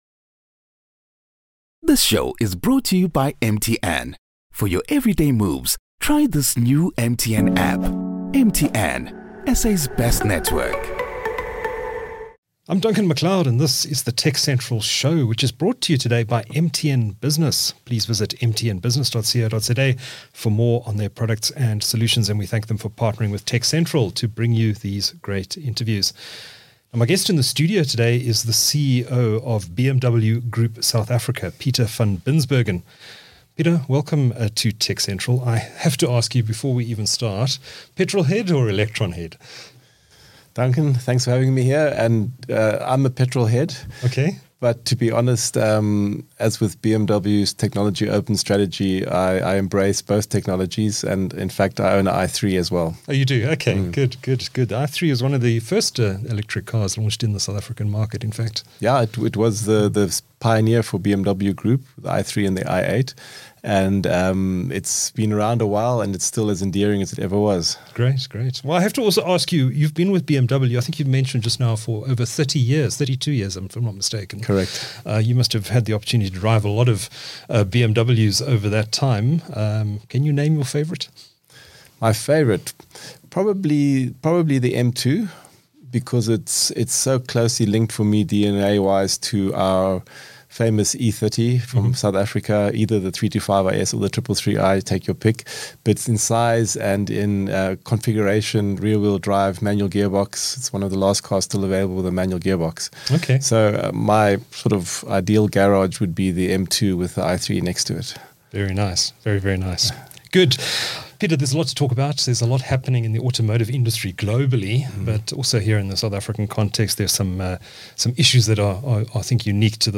...This corrects an audio issue with the previous version... South Africa’s automotive industry is in a state of flux.